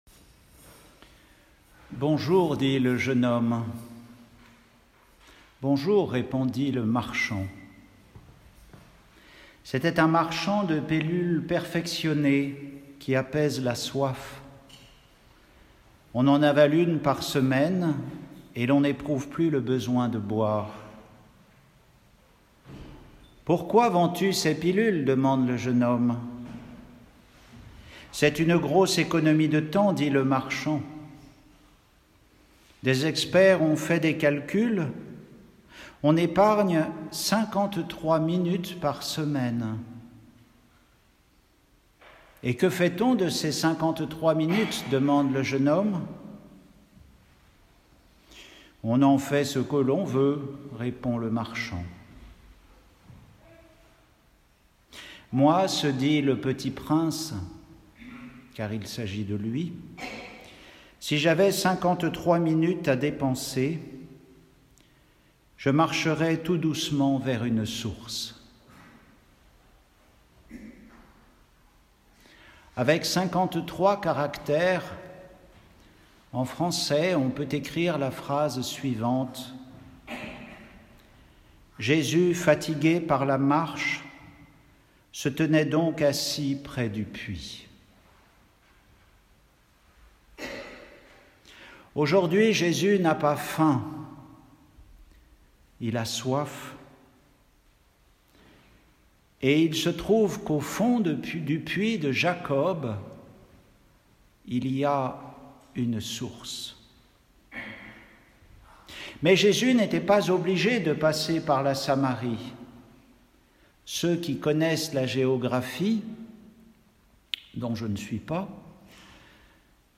Retrouvez les méditations d’un moine sur les lectures de la messe du jour.
Homélie pour le 3e dimanche du Carême